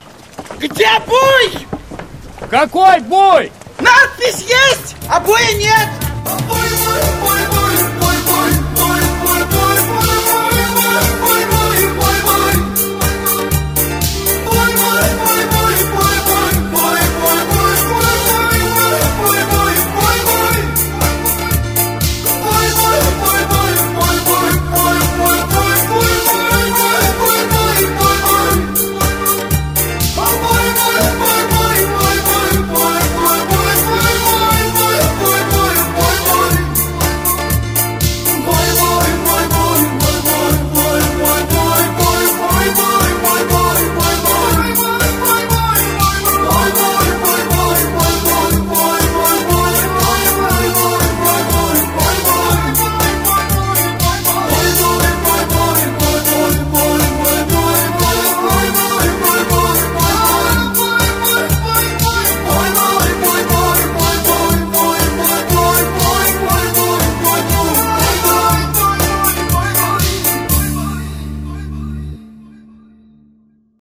• Качество: 320, Stereo
веселые
смех